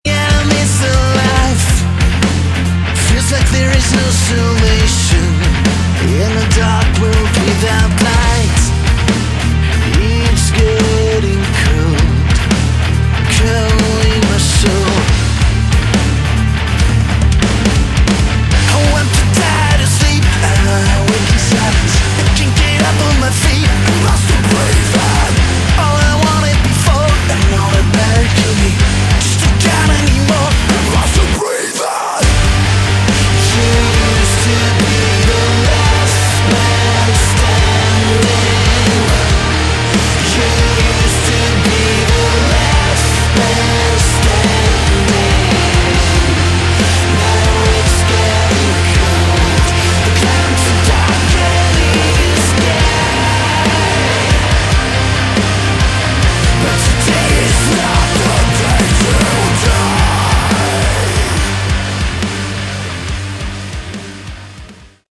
Category: Modern Hard Rock
vocals
bass
keyboards, guitars
drums